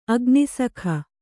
♪ agnisakha